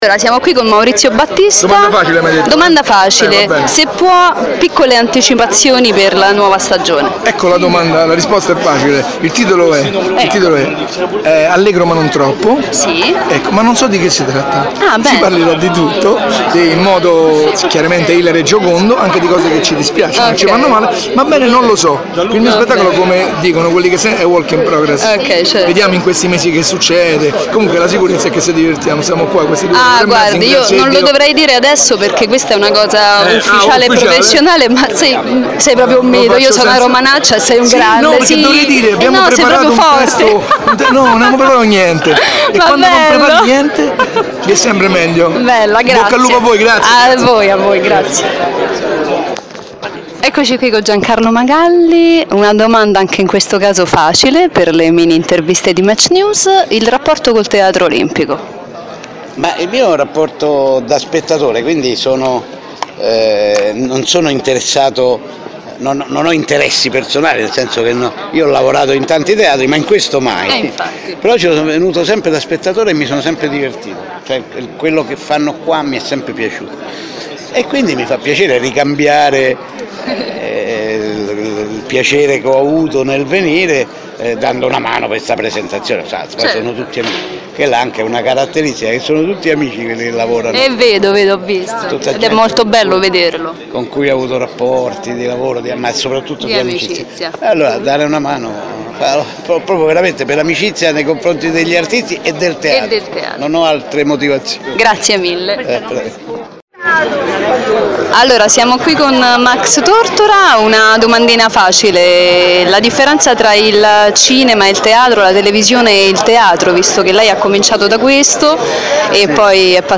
conferenzateatroolimpico.mp3